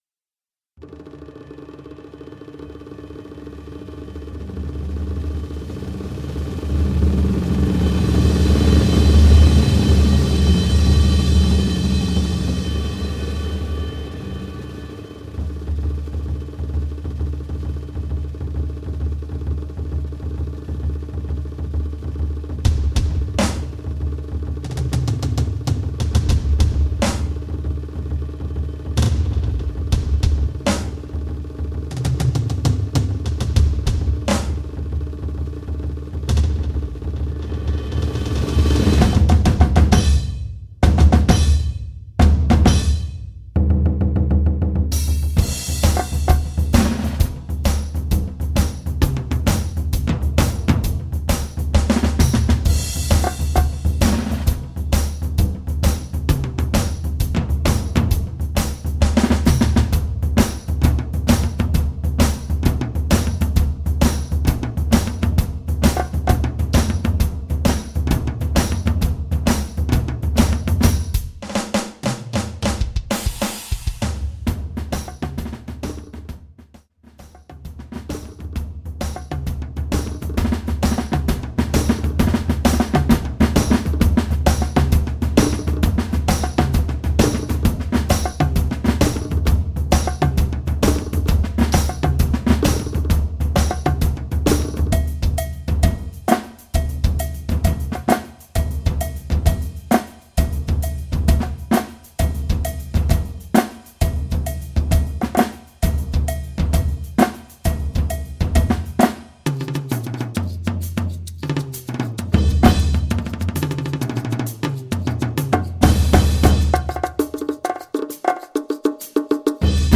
Genre Concert & Contest